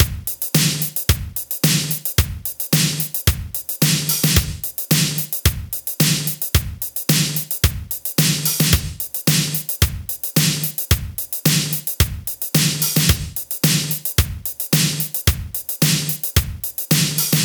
• 110 Bpm Drum Loop Sample D# Key.wav
Free drum groove - kick tuned to the D# note. Loudest frequency: 5875Hz
110-bpm-drum-loop-sample-d-sharp-key-4Xh.wav